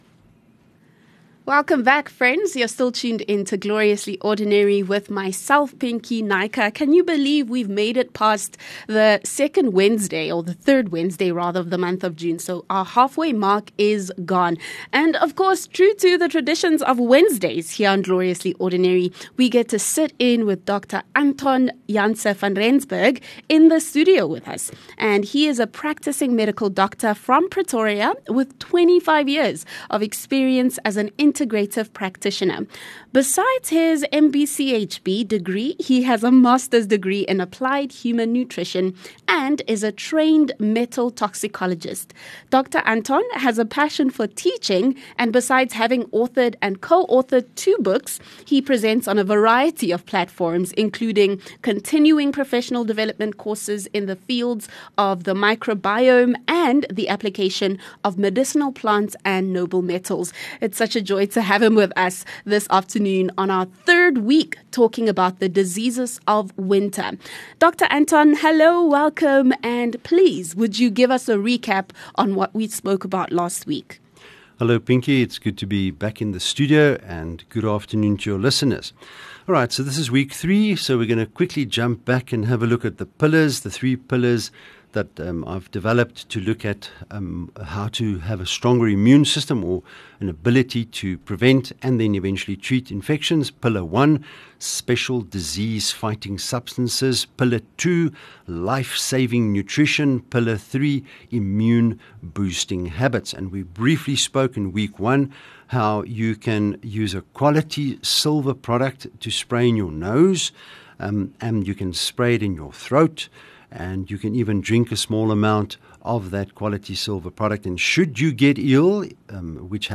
View Promo Continue Install ON-AIR CONTENT 30 Jul SilverLab Healthcare Feature